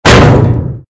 AA_drop_bigweight_miss.ogg